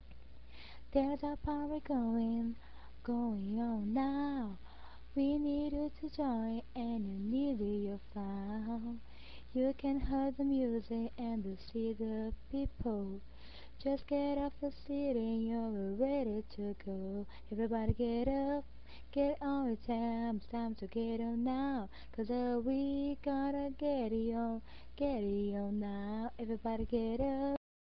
Everything in ( ) is spoken